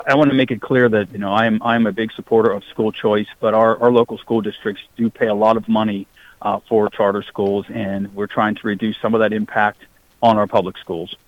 State Representative Jim Struzzi added that the budget does lessen the impact of charter school funding requirements.